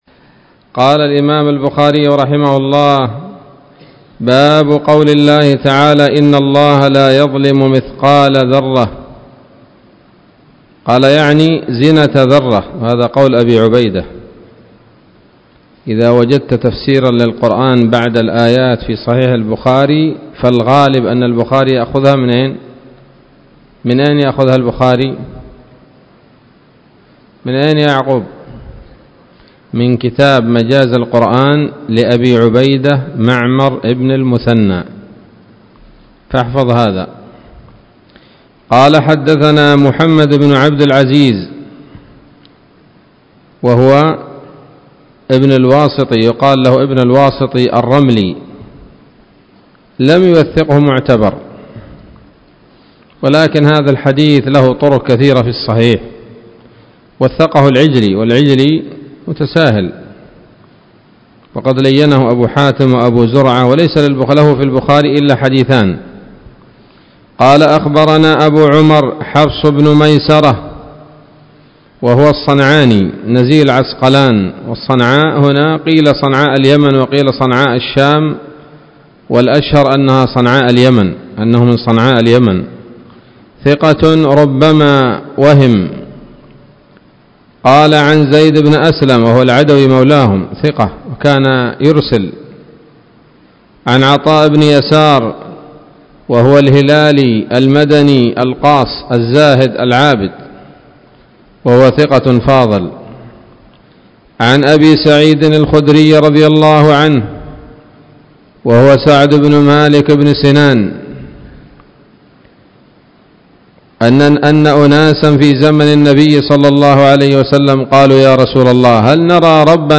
الدرس التاسع والستون من كتاب التفسير من صحيح الإمام البخاري